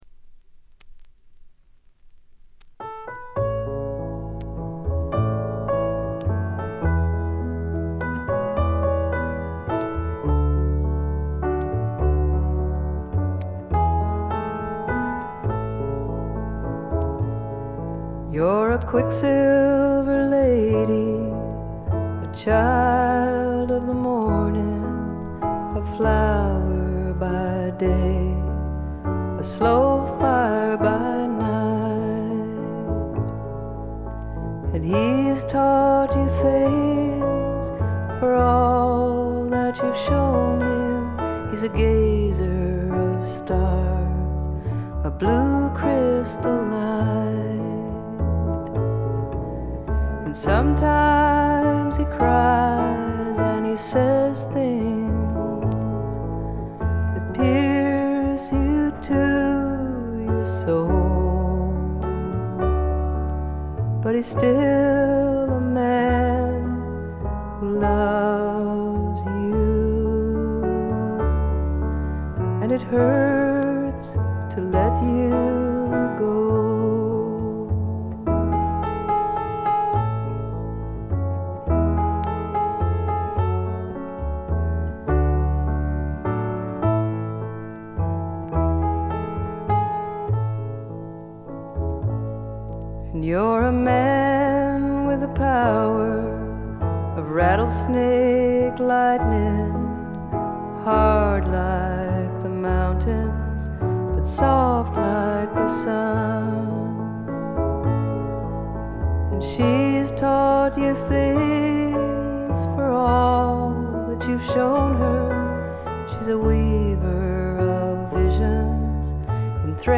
しみじみとした歌の数々が胸を打つ女性フォーキー・シンガーソングライターの基本です。
試聴曲は現品からの取り込み音源です。
vocals, guitar, piano